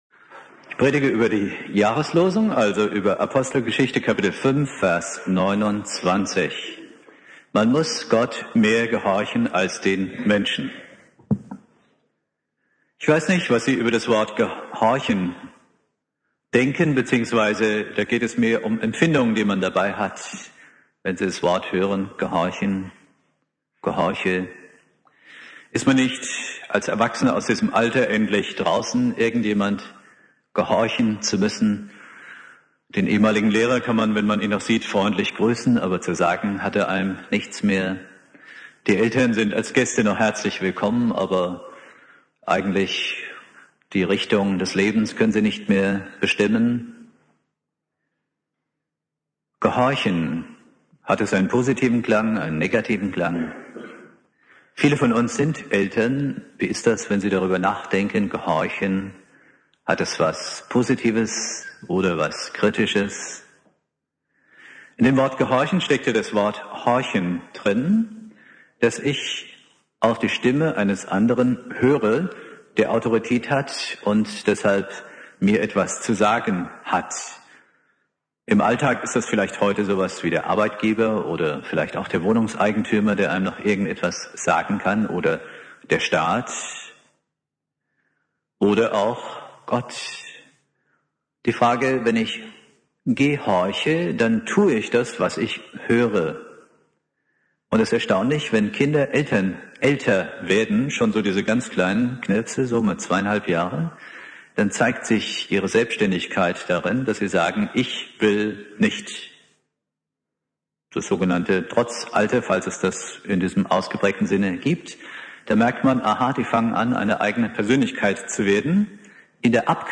Predigt
Neujahr